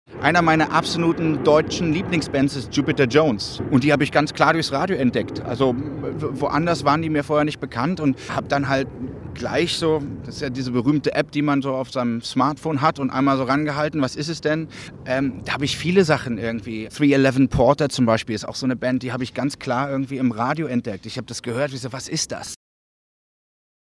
Paul van Dyk verrät, dass er Jupiter Jones durchs Radio kennen- und liebengelernt hat. (Interview